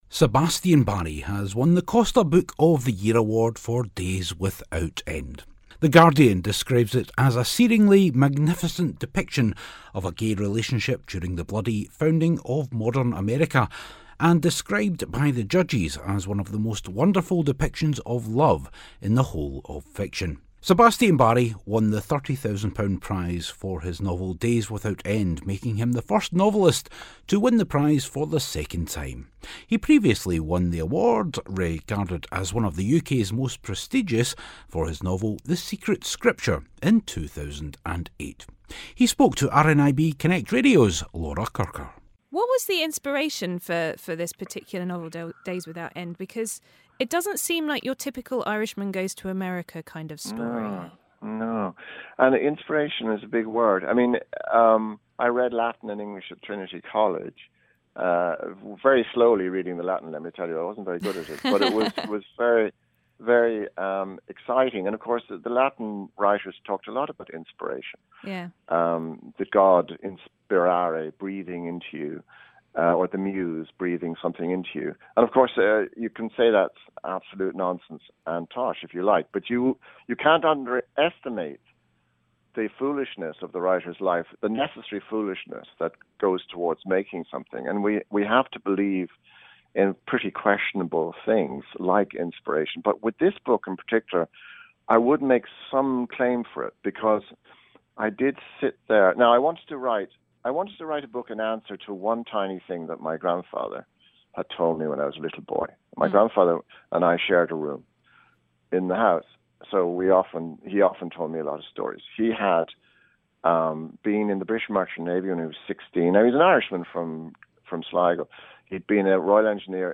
Costa Book of the Year - a chat with winner Sebastian Barry.